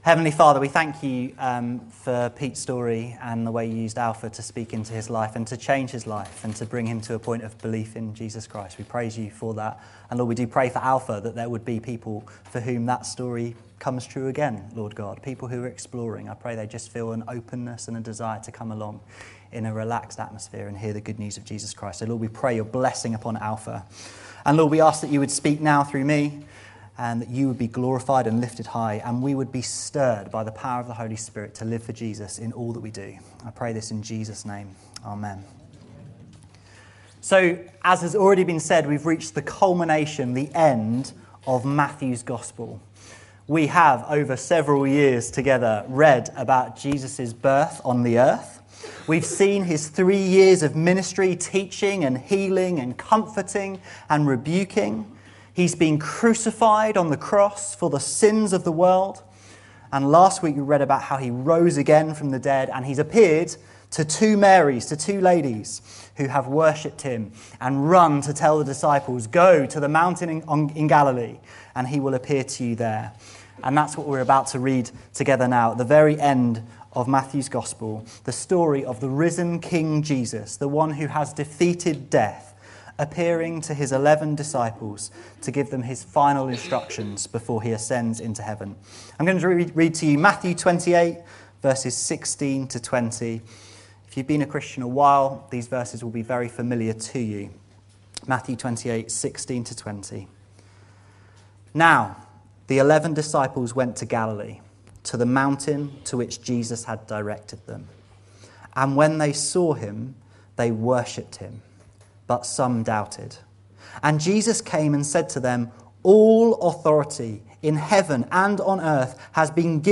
This sermon unpacking these verses answers two important questions, Who is Jesus? and What does Jesus command us to do?